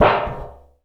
metal_tin_impacts_hit_hard_04.wav